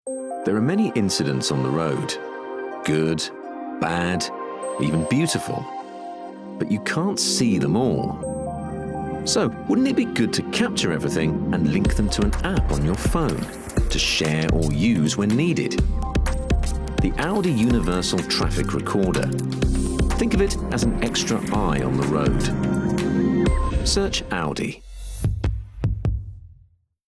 30/40's London/Neutral, Deep/Engaging/Comedy
• Commercial